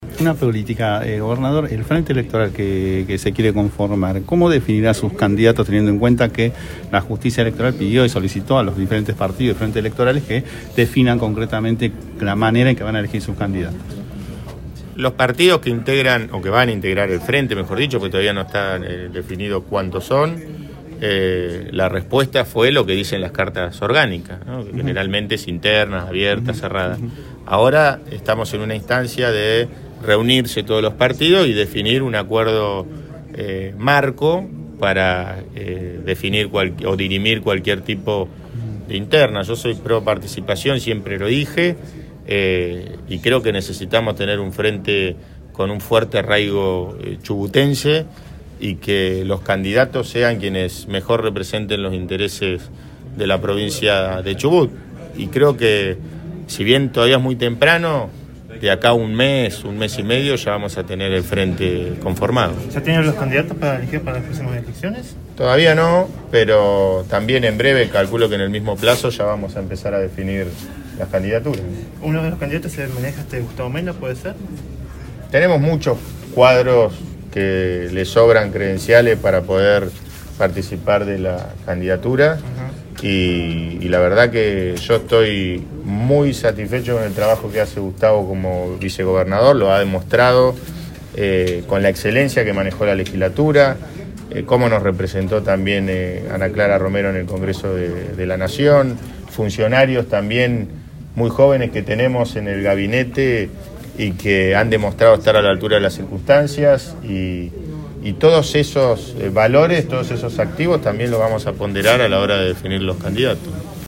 En la conferencia de prensa de hoy el gobernador habló de todo